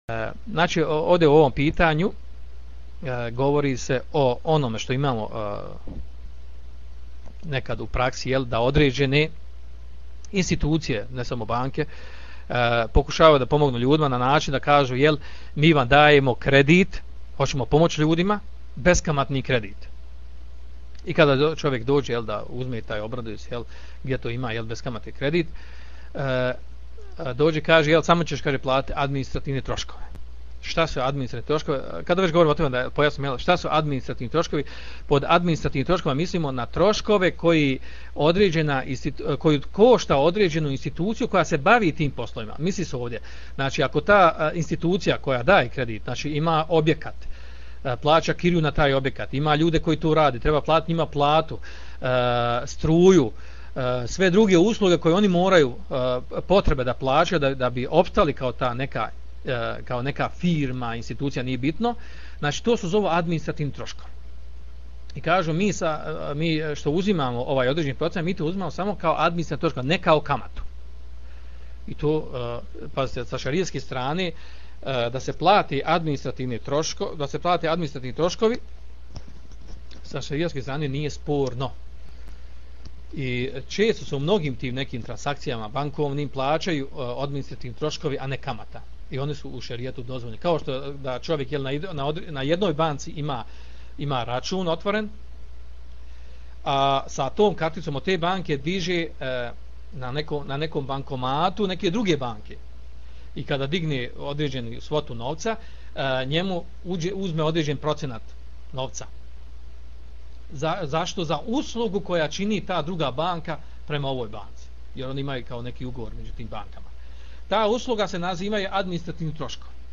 Poslušajte audio isječak iz predavanja